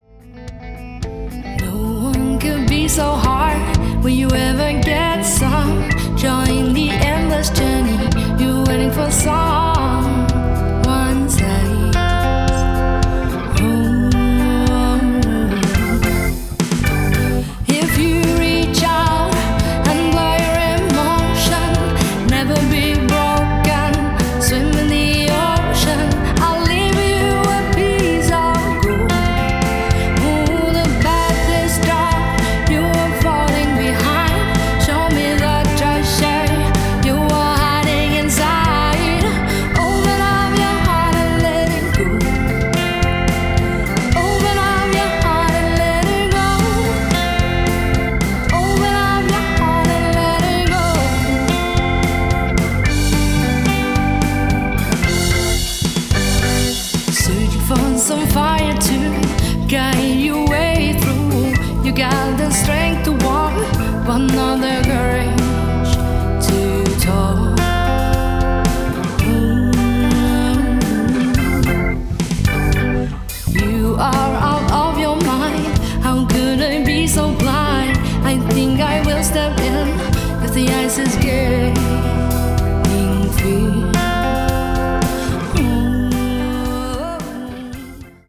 • Pop
forsanger
guitar og kor
trommer og percussion
keyboard og kor